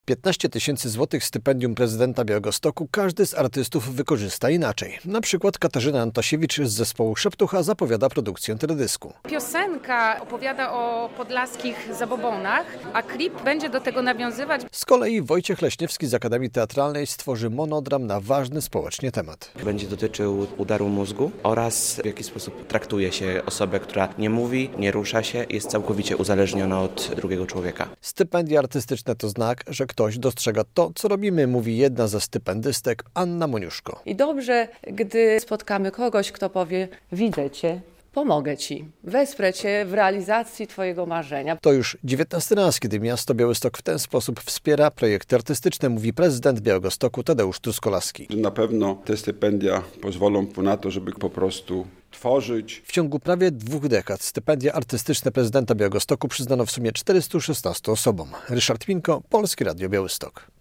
30 osób odebrało stypendia artystyczne prezydenta Białegostoku - relacja